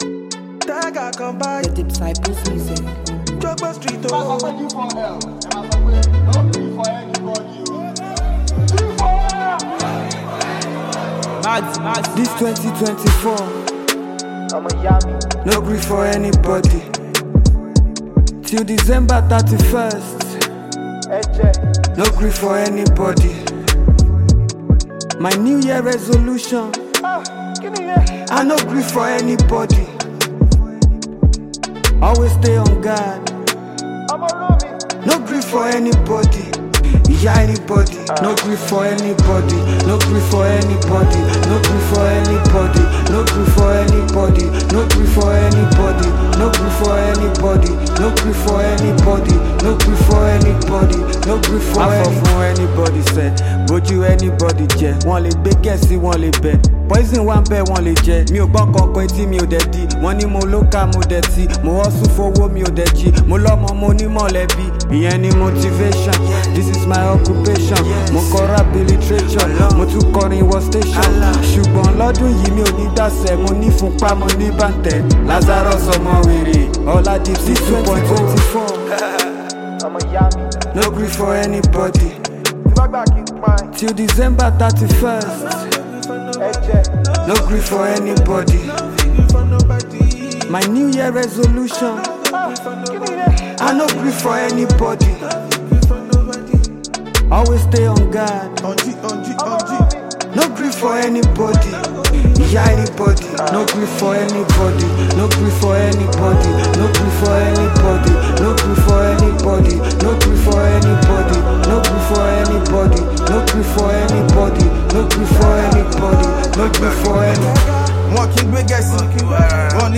Nigerian rapper, singer and songwriter